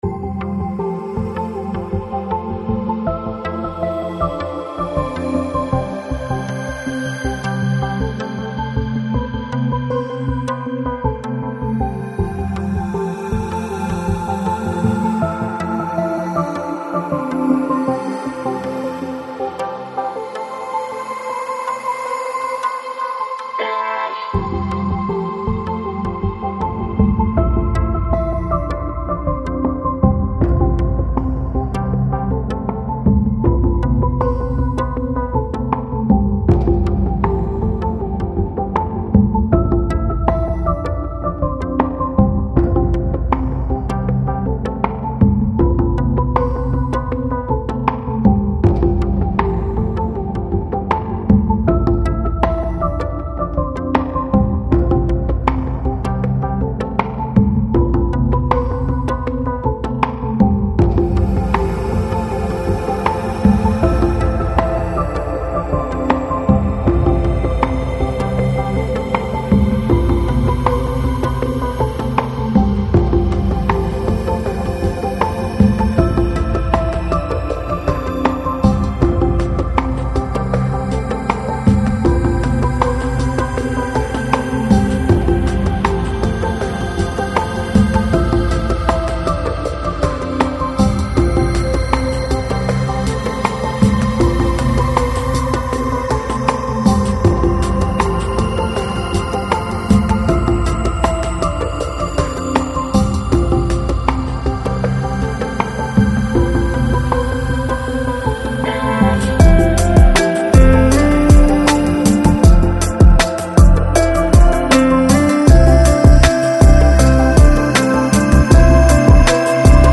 Жанр: Downtempo, Lounge, Balearic